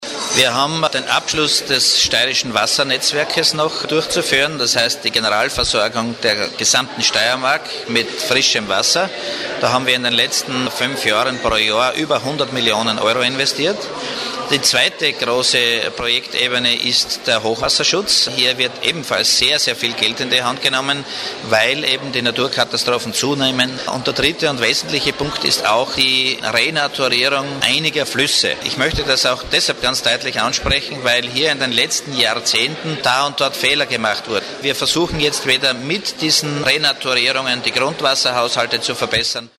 Statements
Fragen an Landesrat Johann Seitinger.